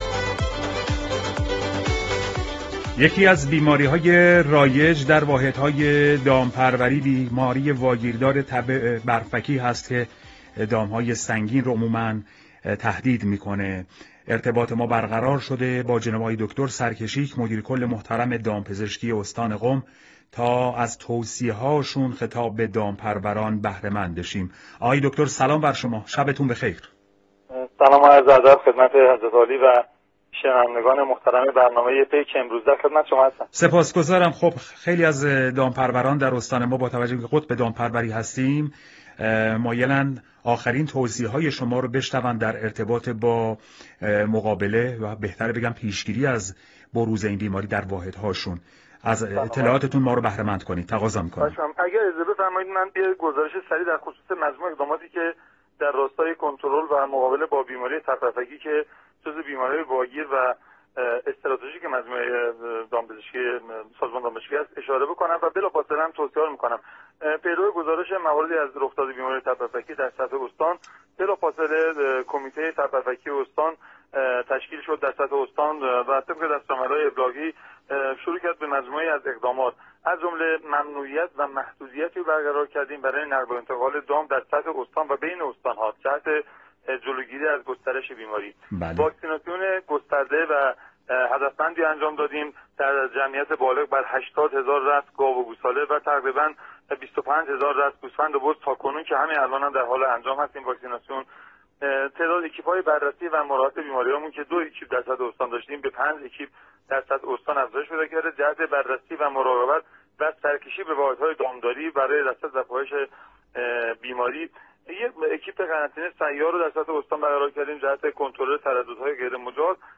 مصاحبـه رادیـویـی مدیــرکل دامپــزشکی استان در خصوص تب بـرفکـی